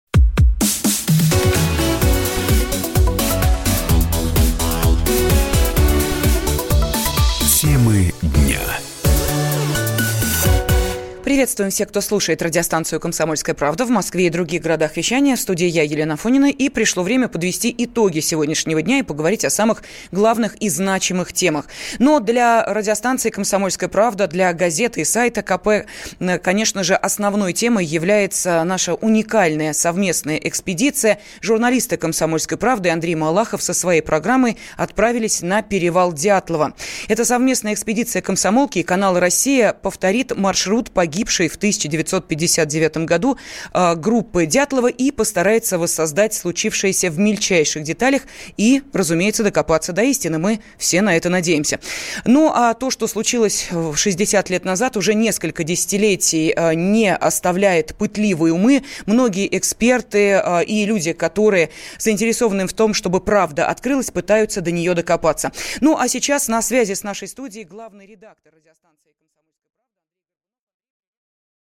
Аудиокнига Первые результаты экспертиз: Повреждения на телах туристов не укладываются в «лавинную версию» | Библиотека аудиокниг